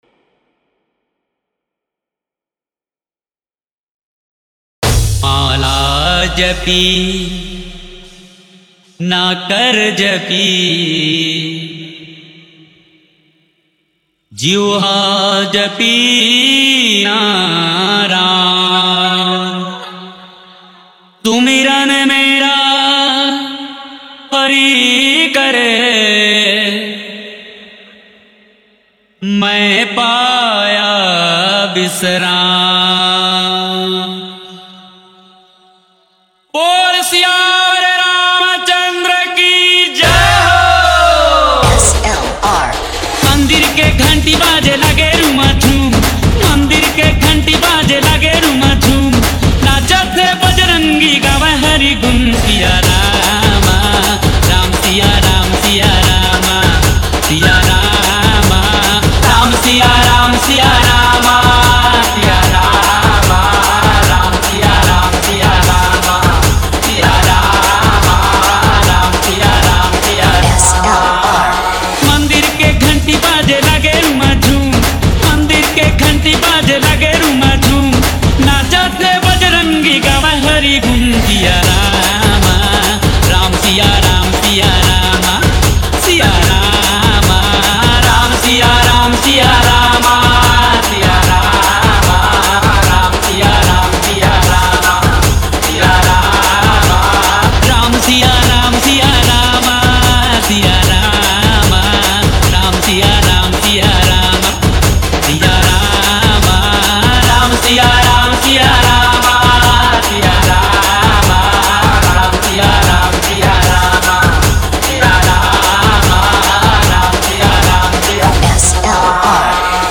- bhakti dj song